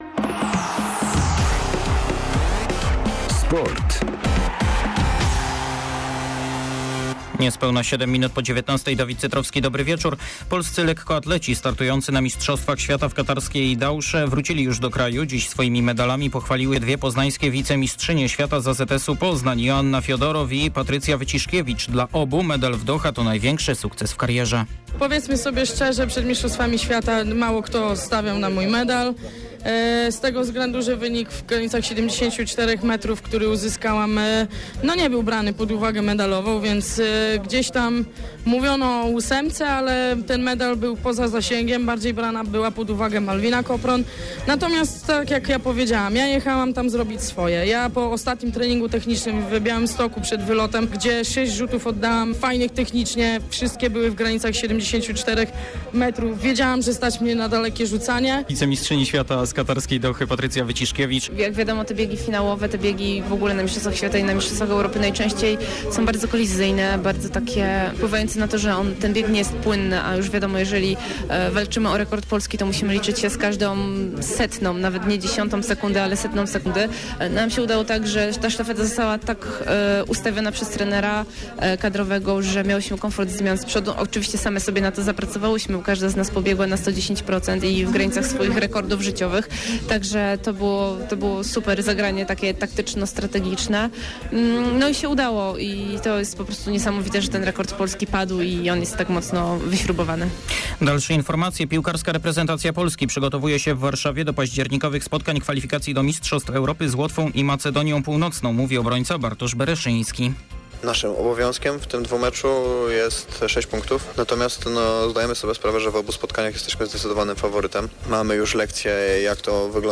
08.10. SERWIS SPORTOWY GODZ. 19:05